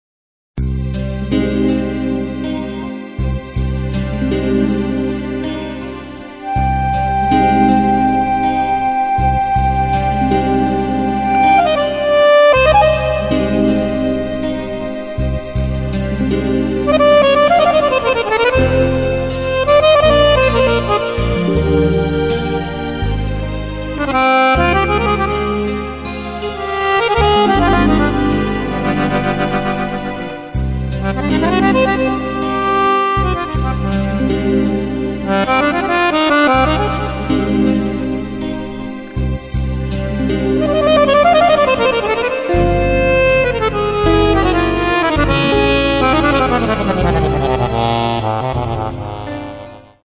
Playback + Akkordeon noten